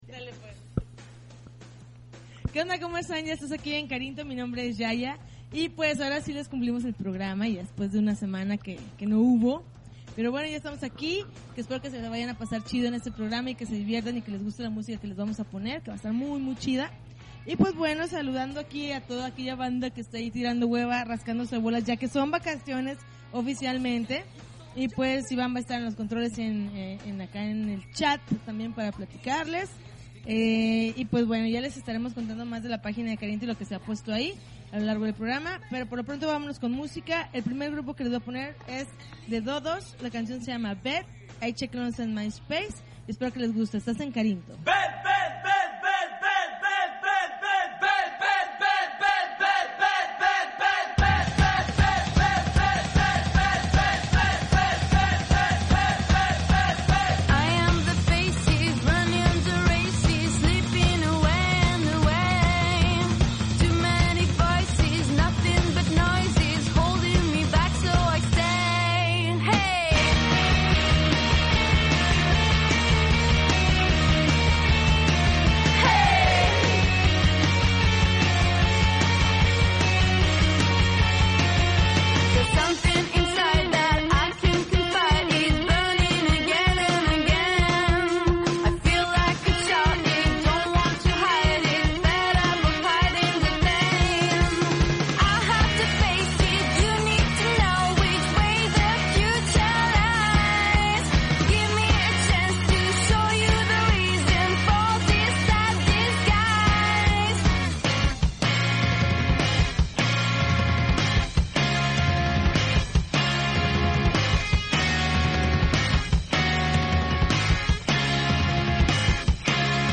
August 2, 2009Podcast, Punk Rock Alternativo